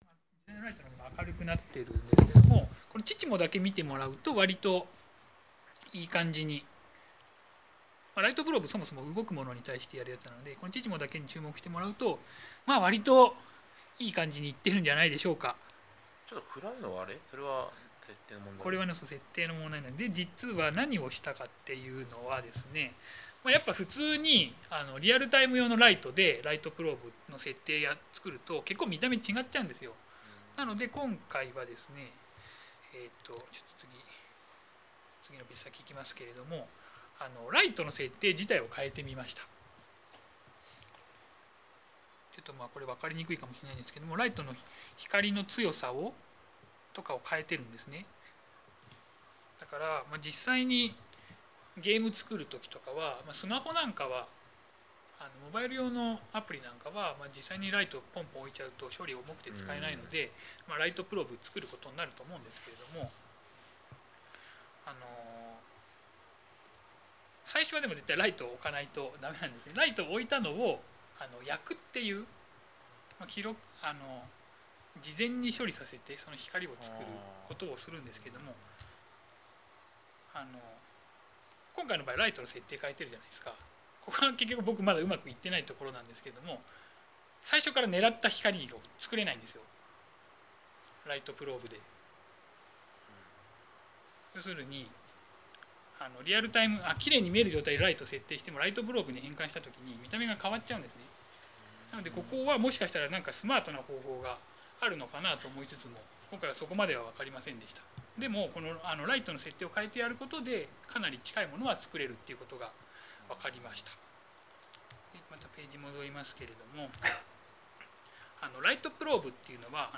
프레젠테이션 음성